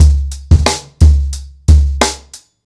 • 90 Bpm Breakbeat C# Key.wav
Free drum groove - kick tuned to the C# note. Loudest frequency: 989Hz
90-bpm-breakbeat-c-sharp-key-U0Y.wav